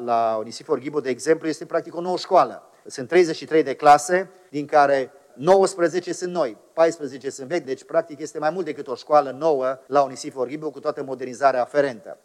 Tot atunci ar urma să fie finalizate și lucrările de modernizare a liceului „Ana Aslan”, dar și cele de la una dintre clădirile celei mai populate școli din oraș, liceul „Onisifor Ghibu”. Aici vor fi 19 clase noi, spune primarul Emil Boc: